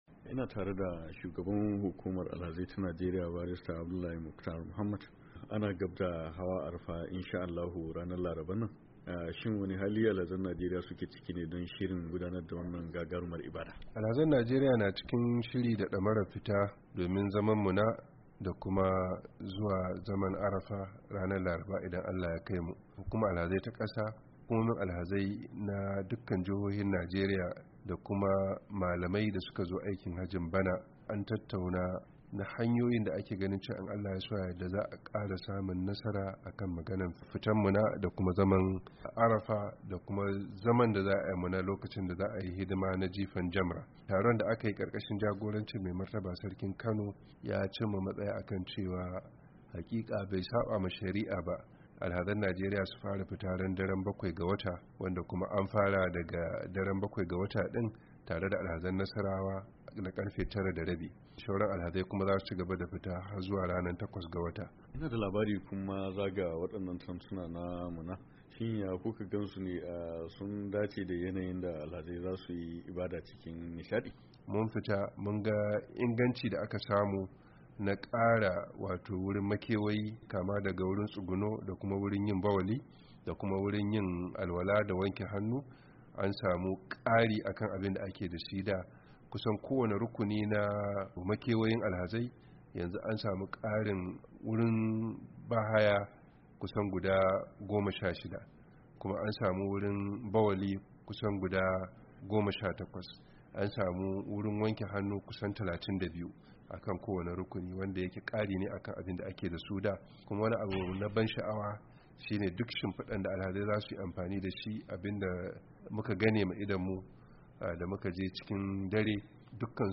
Shugaban hukumar alhazai ta Najeriya Barrister Abduulahi Mukhtar Muhammad ya zanta da ,uryar Amurka akan shirin alhazan Najeriya dake aikin ibada na wannan shekarar a kasa mai tsarki